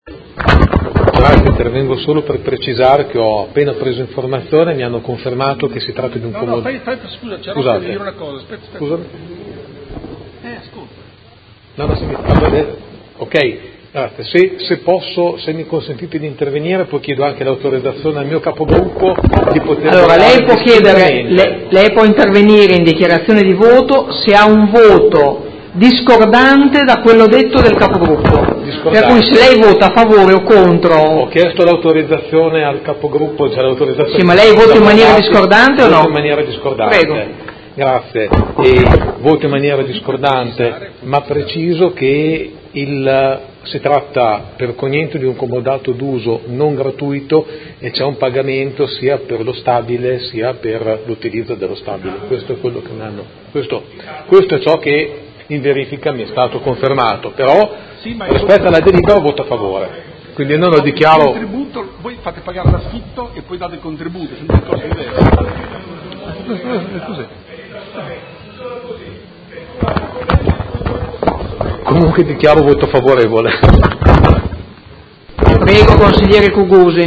Seduta del 23/11/2017 Dichiarazione di voto. Concessione in diritto di superficie a titolo oneroso a favore dell’Associazione Il Tortellante APS di una porzione immobiliare posta a Modena all’interno del comparto denominato “Ex MOI di Via C. Menotti” – Approvazione